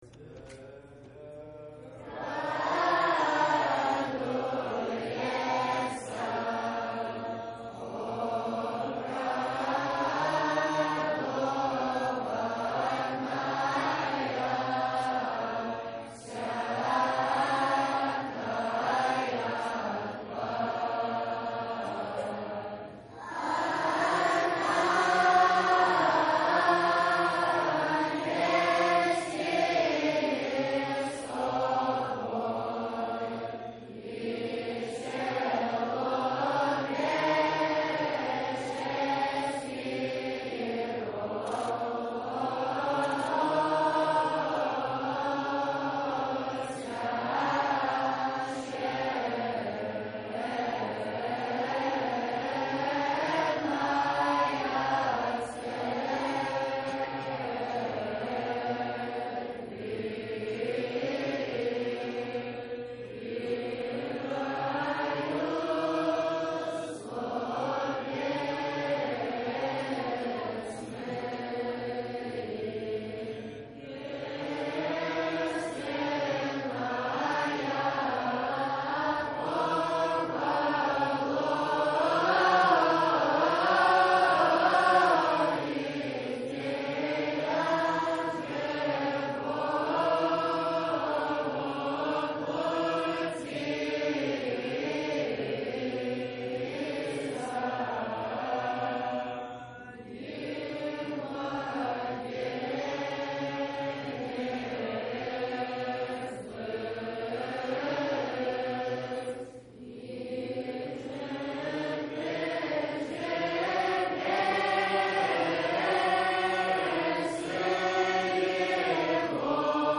Choir Sings 2 .
Choir2.MP3